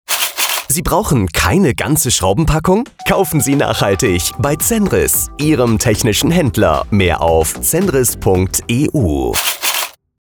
Hier schonmal der Radiospot.
Ich hab noch versucht das Schütteln der Schachtel vom Anfang und Ende des Radiospots aufzunehmen, das das hat nicht so gut geklappt.
Zenris-Radiospot.mp3